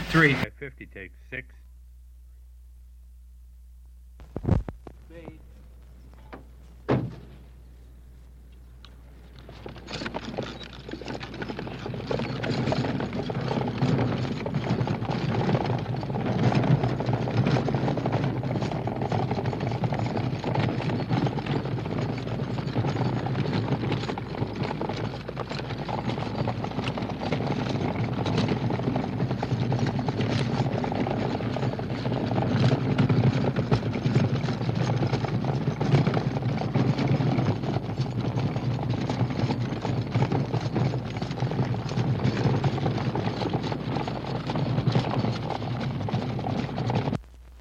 古老的马车 " G5203 舞台车出口
描述：舞台教练退出门砰击和大量的大头钉，雷鸣般的马驰骋。
我已将它们数字化以便保存，但它们尚未恢复并且有一些噪音。